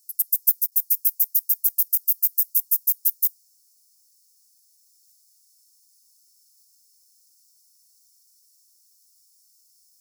toad shieldback
10 s of calling song and waveform. Baja California, Mexico; 23.5°C. S89-21, R89-25.